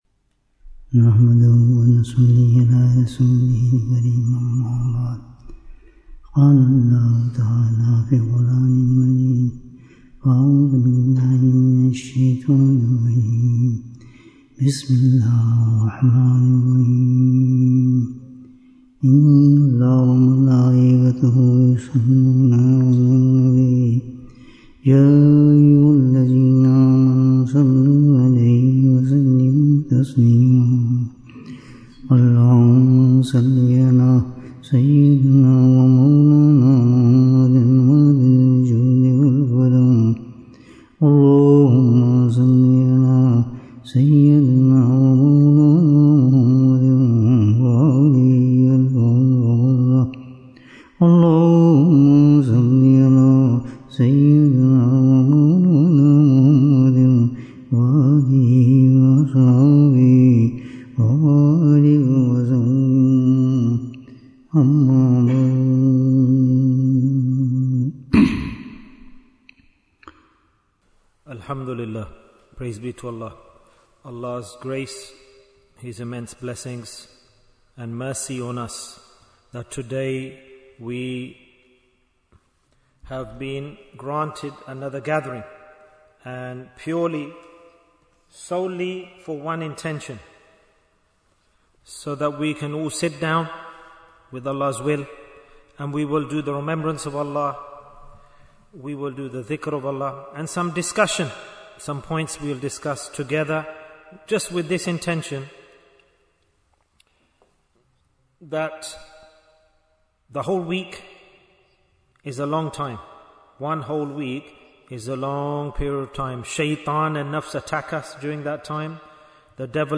Which Ibaadah Should We Do For Maghfirah? Bayan, 80 minutes2nd October, 2025